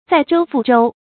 載舟覆舟 注音： ㄗㄞˋ ㄓㄡ ㄈㄨˋ ㄓㄡ 讀音讀法： 意思解釋： 載：承載；覆：顛覆；傾覆。